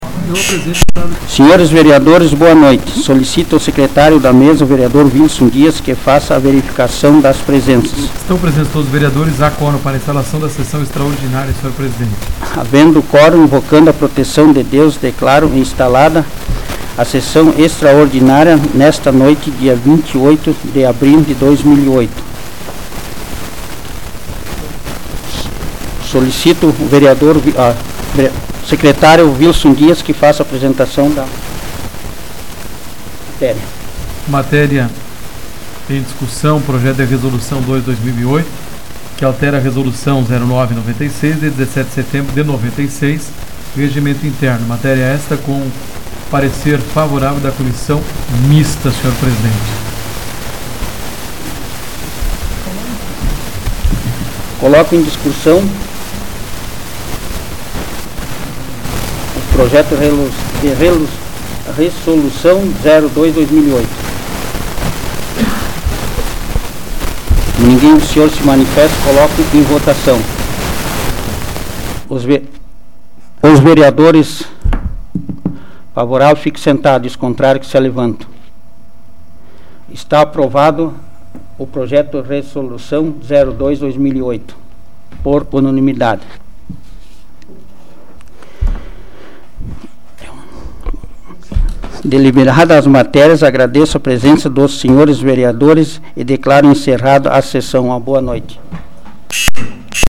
Áudio da 49ª Sessão Plenária Extraordinária da 12ª Legislatura, de 28 de abril de 2008